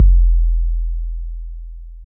Kick SwaggedOut 5.wav